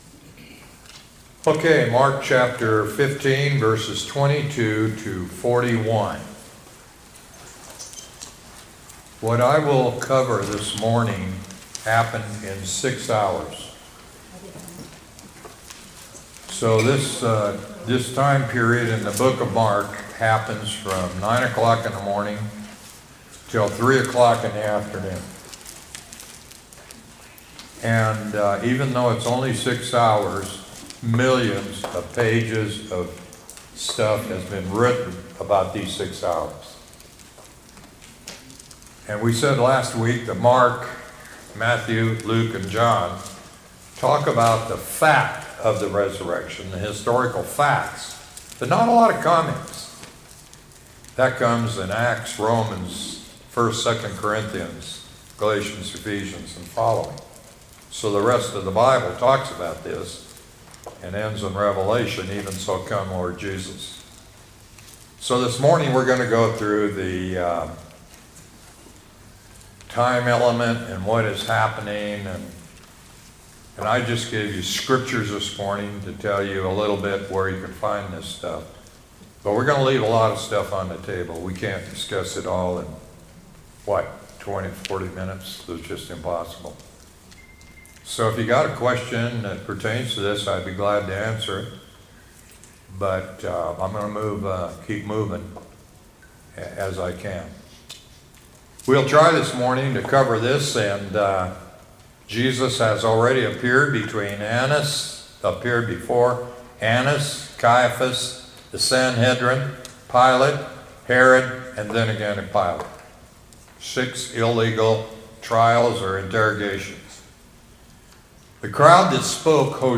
Sermons Podcast - The Crucifixion of Christ | Free Listening on Podbean App